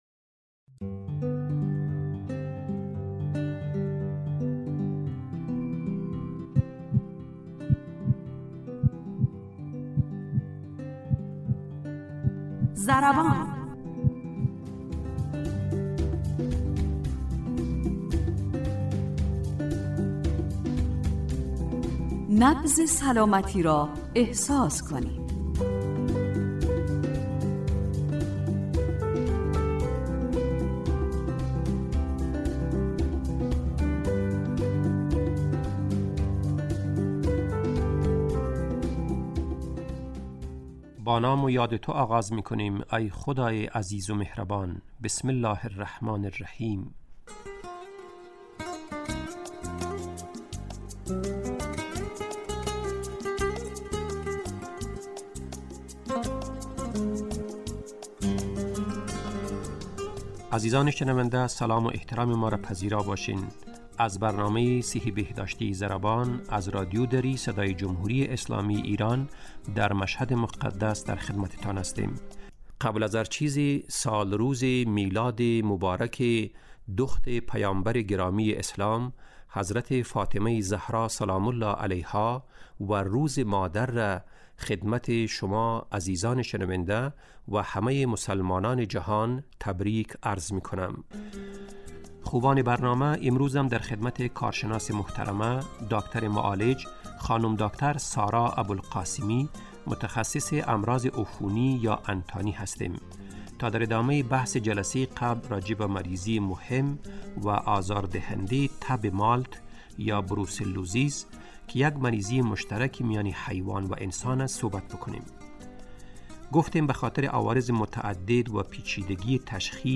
برنامه ای صحی و بهداشتی است که با استفاده از تجربیات کارشناسان حوزه بهداشت و سلامت و استادان دانشگاه، سعی دارد مهمترین و شایع ترین مشکلات صحی و بهداشتی جامعه افغانستان را مورد بحث و بررسی قرار دهد.